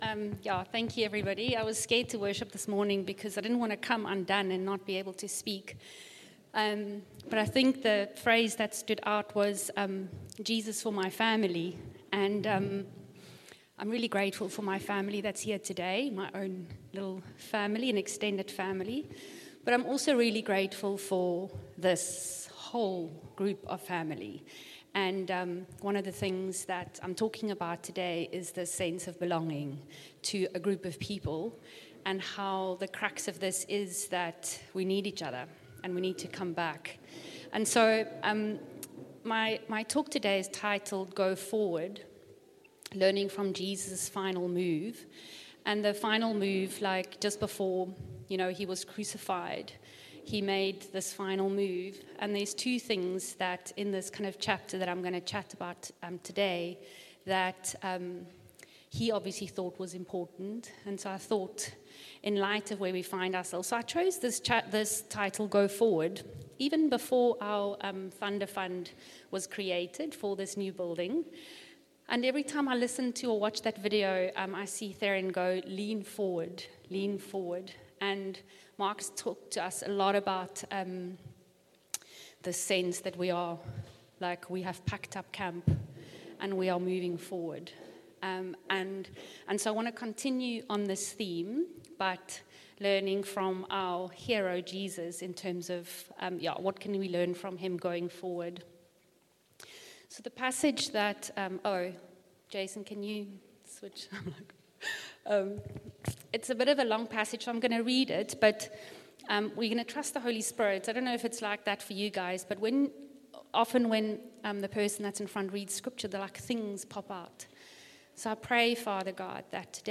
Sunday Service – 28 July